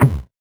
CS_VocoBitA_Hit-15.wav